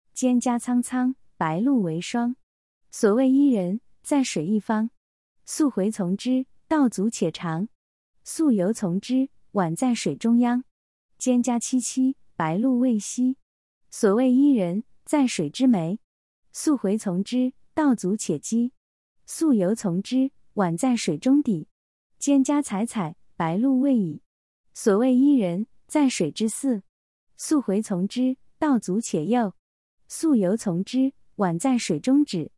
それぞれに中国語の朗読音声も付けておりますの韻を含んだリズム感を聞くことができます。